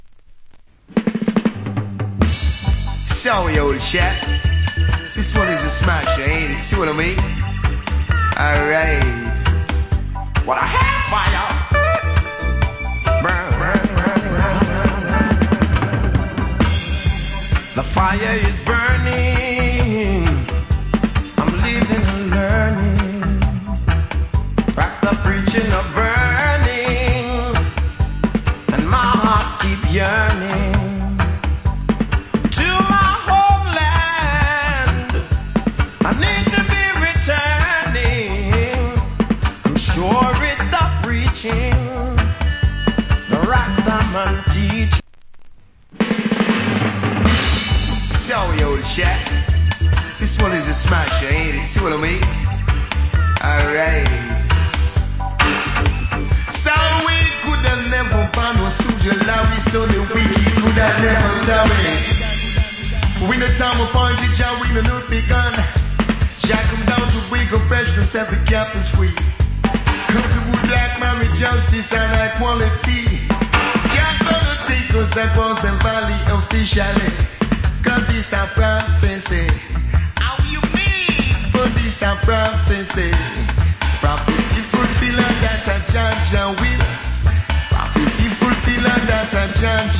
Cool Roots.